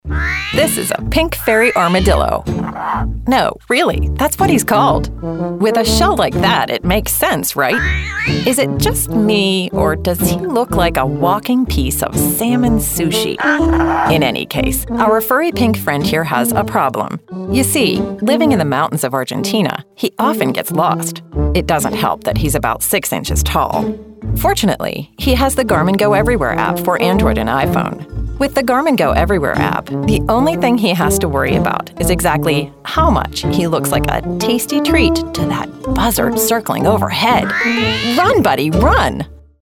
Explainer_Garmin_amusing, athletes, professionals, travelers
Neutral, Mid-Atlantic
Middle Aged